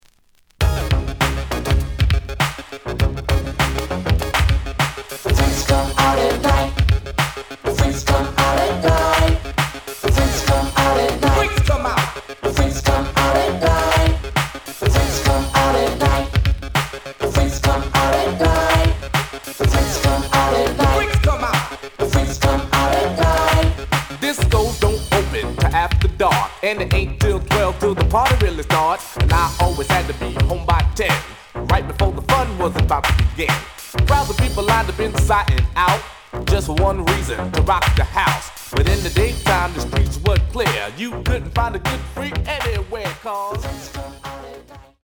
The audio sample is recorded from the actual item.
●Genre: Hip Hop / R&B
Slight edge warp.